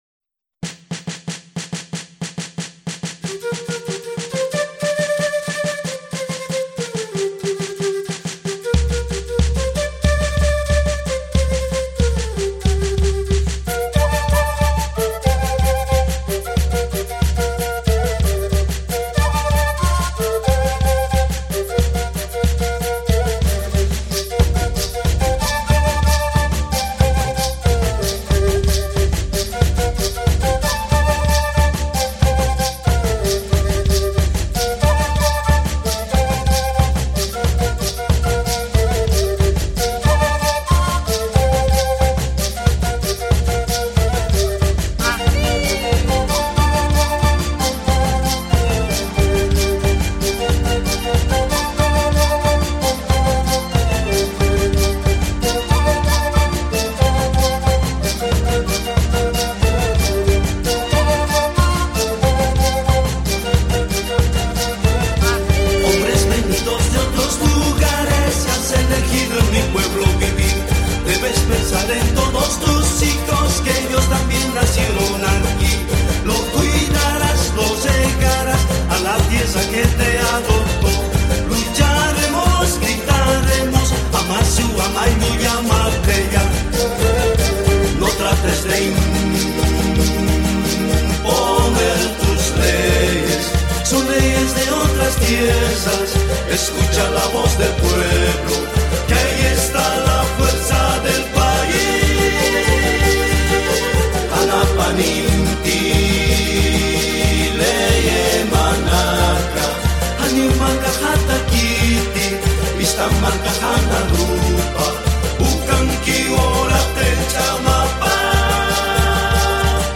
Trough the sounds of panpipes